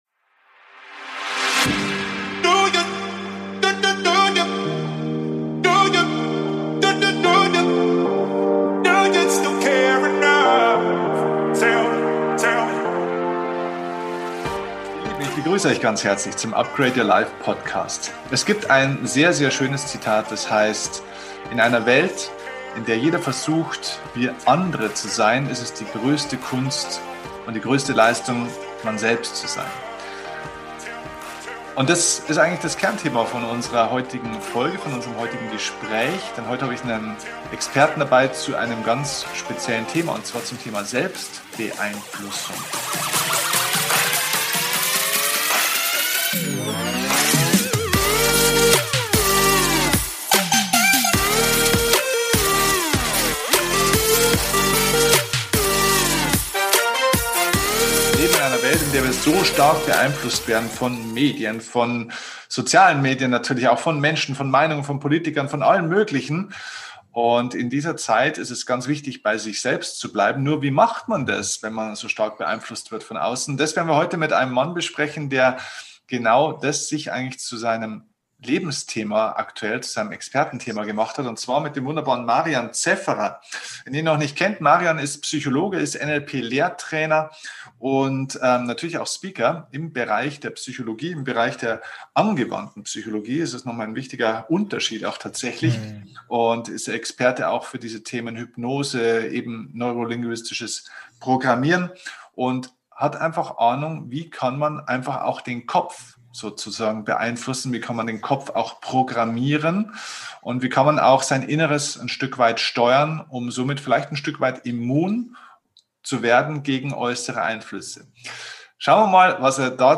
Die Psychologie der Selbstbeeinflussung - Interview